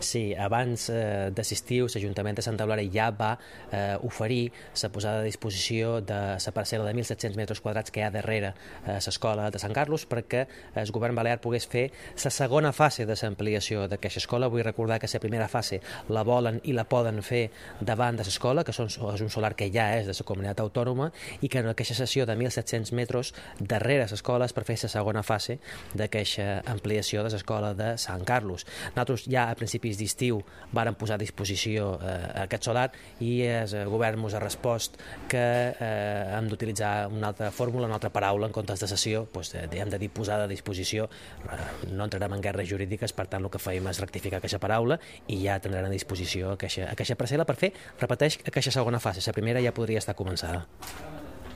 Corte de voz Concejal de Urbanismo Mariano Juan- Colegio Sant Carles